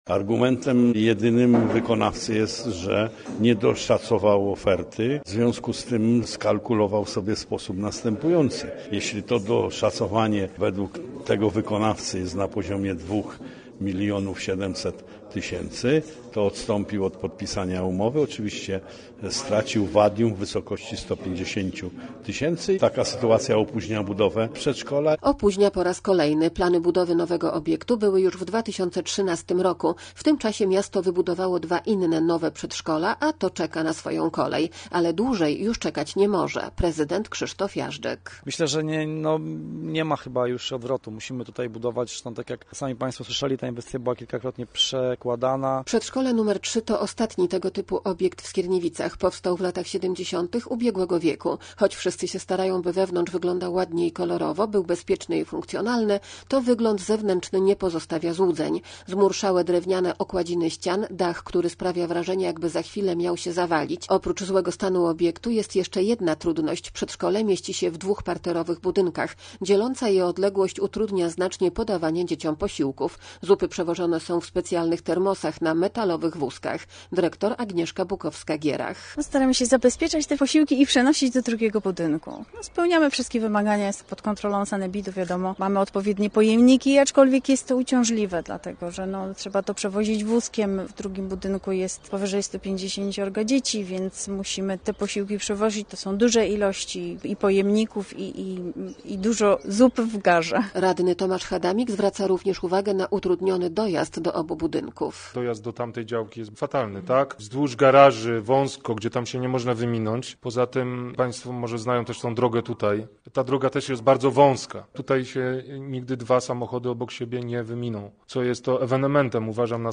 Posłuchaj relacji i dowiedz się więcej: Nazwa Plik Autor Co dalej z przedszkolem w Skierniewicach? audio (m4a) audio (oga) Warto przeczytać Fly Fest 2025.